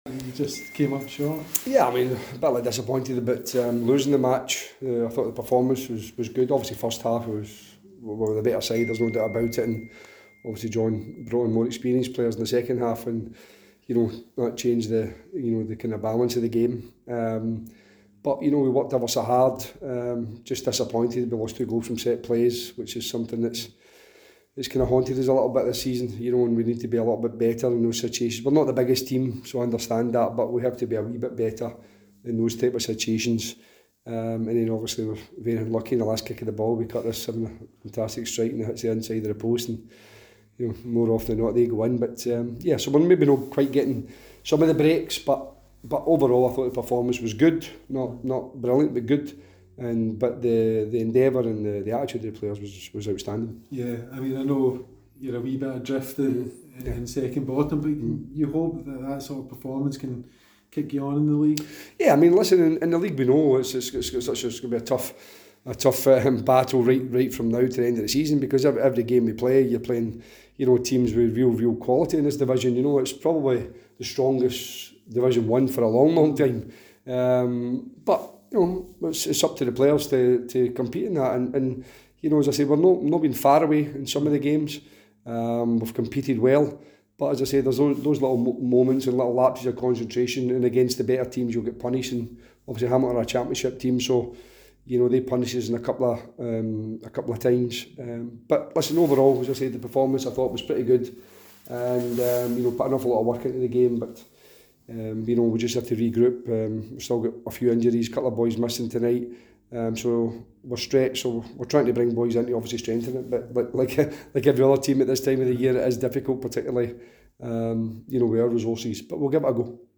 post-match comments following the SPFL Trust Trophy tie